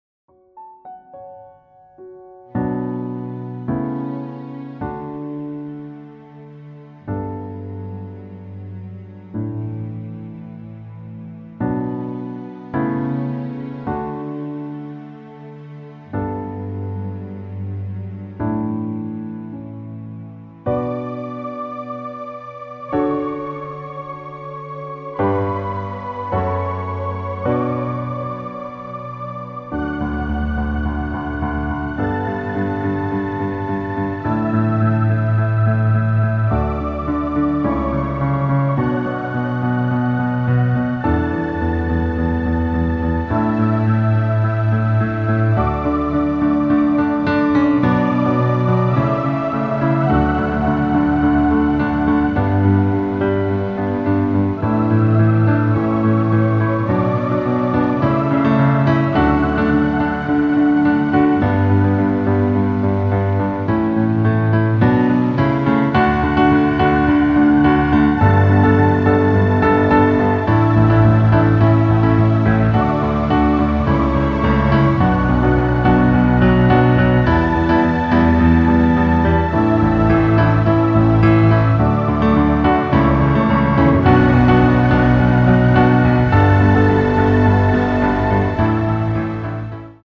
Klavierversion
• Tonart: A Dur
• Art: Flügeleinspielung
• Das Instrumental beinhaltet keine Leadstimme
Lediglich die Demos sind mit einem Fade-In/Out versehen.
Klavier / Streicher